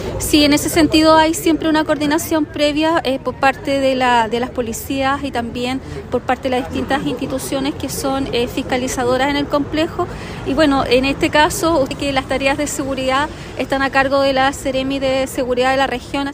La delegada presidencial provincial de Osorno, Claudia Pailalef, explicó que en estos casos la coordinación con las policías son vitales.